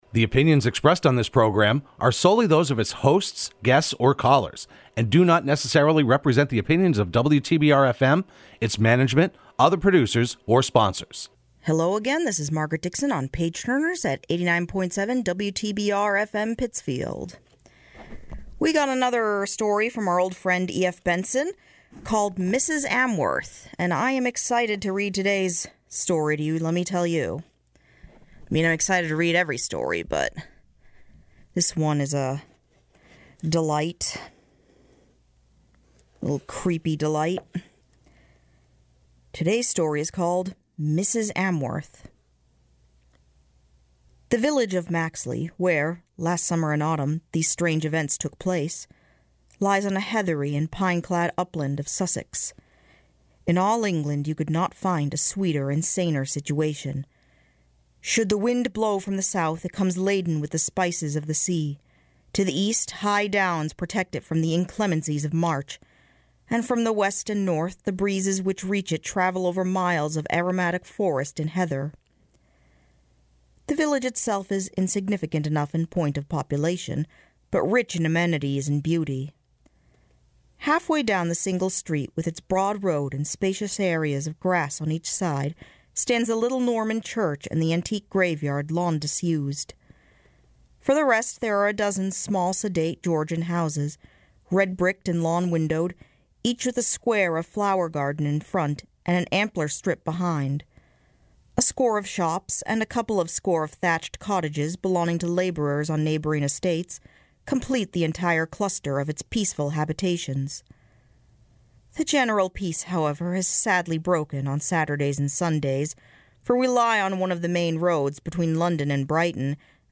Page Turners can be heard every Sunday morning at 7am on WTBR.